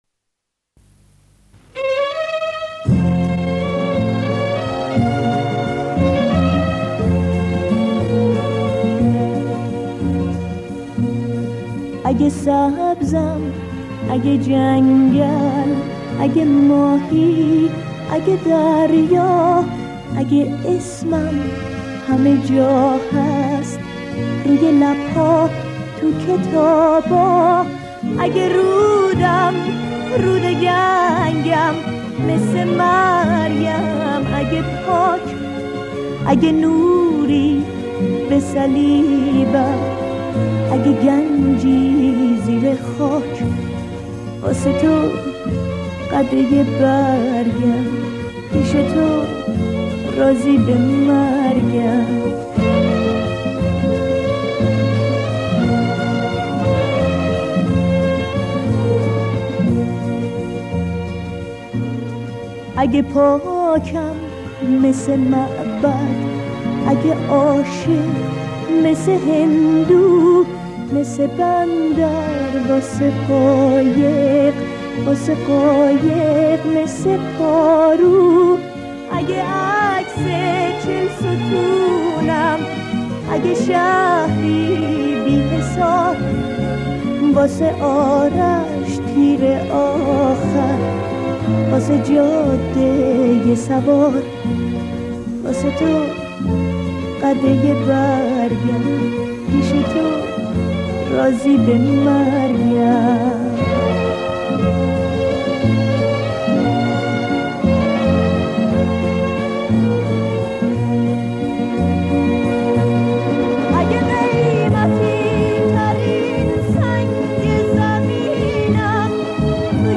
اجرا در تلویزیون کشور اردن / ۱۳۵۴ هجری خورشیدی